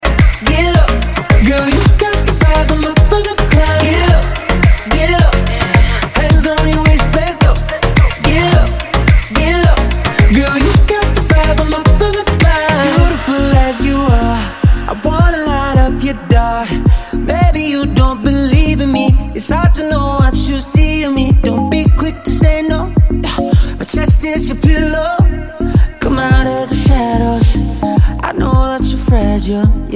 Dance - Electronics